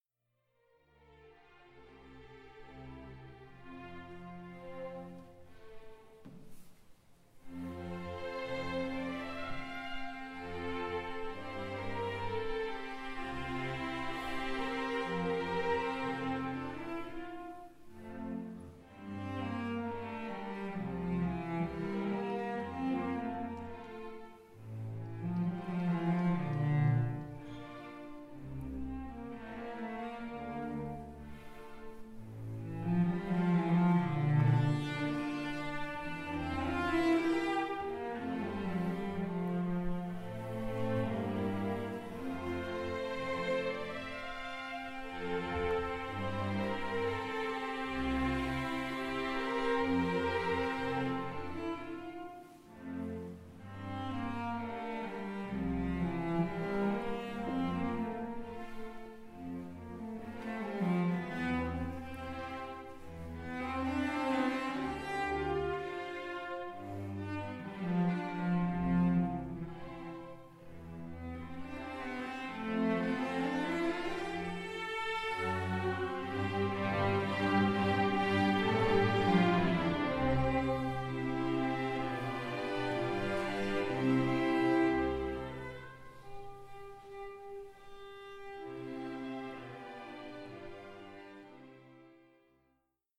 Ausschnitt aus dem 2.Satz, Adagio.